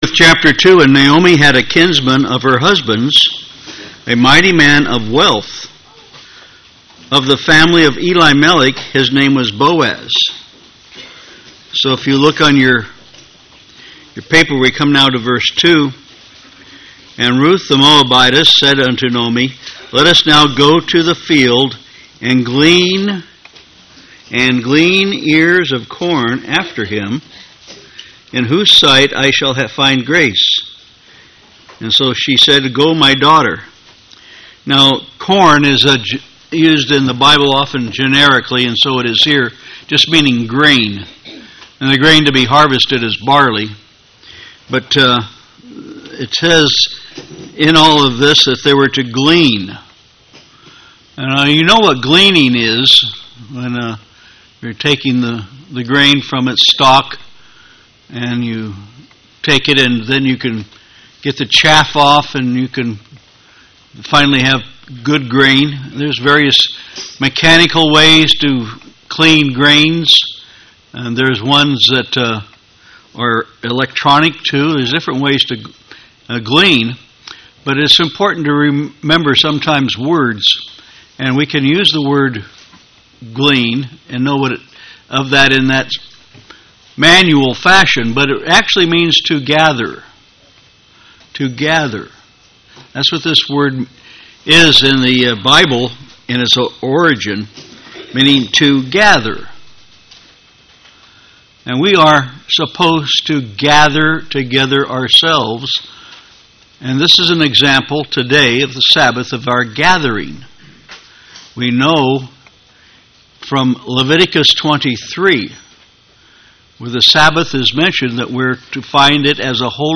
Bible study of the book of Ruth by chapter.